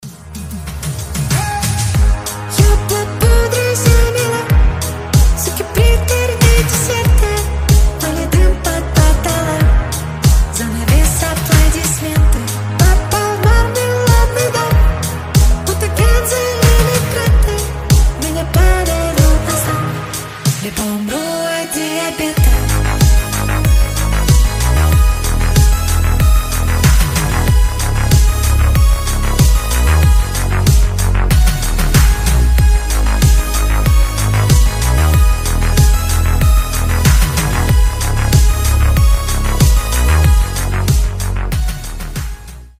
• Качество: 320, Stereo
мужской голос
диско